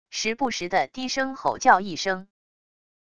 时不时的低声吼叫一声wav音频